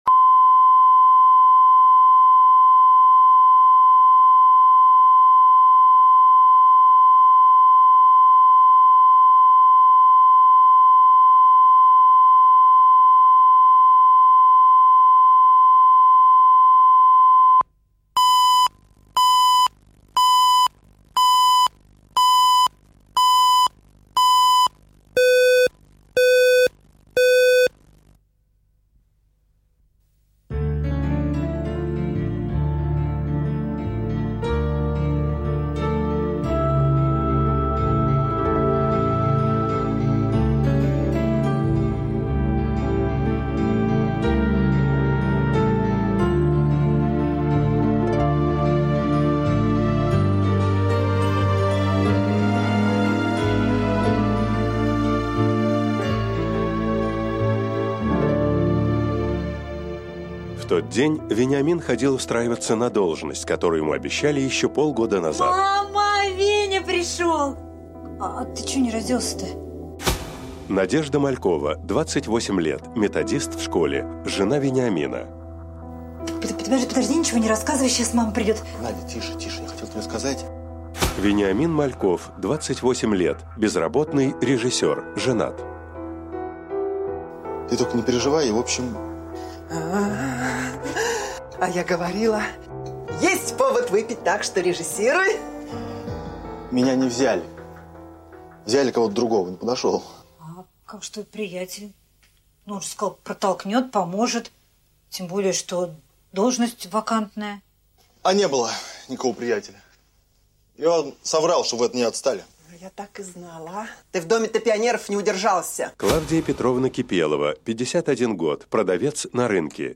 Аудиокнига Веник | Библиотека аудиокниг